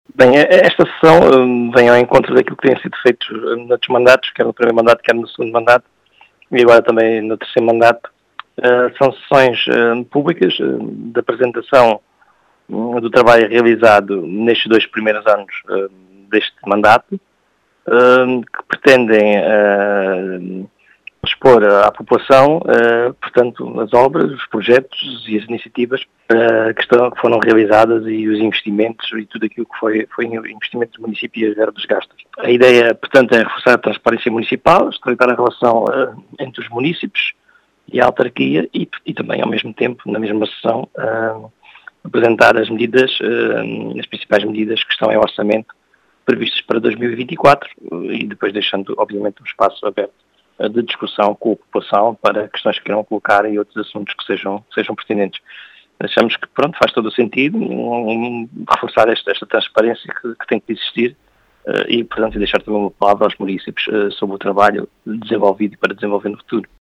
As explicações são de João Português, presidente da Câmara Municipal de Cuba.